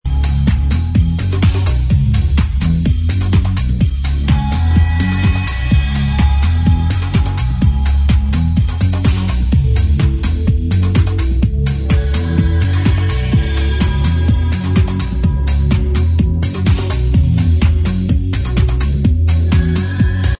2005-2006 house